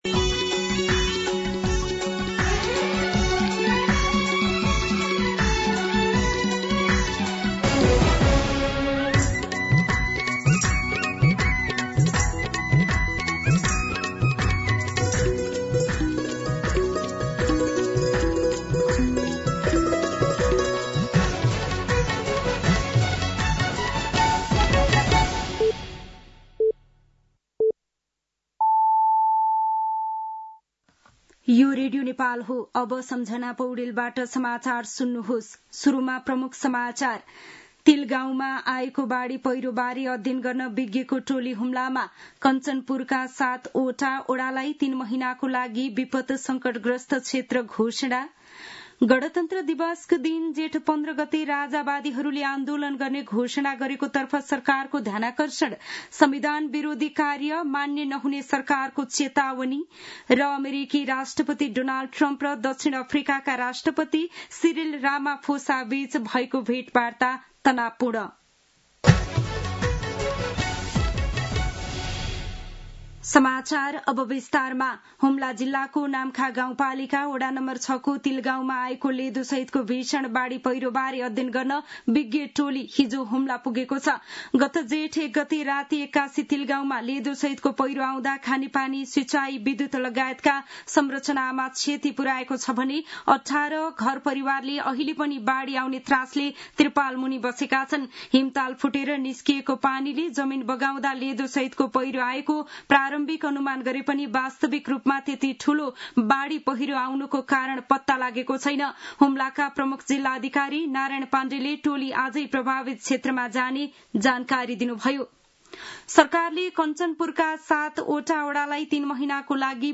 दिउँसो ३ बजेको नेपाली समाचार : ८ जेठ , २०८२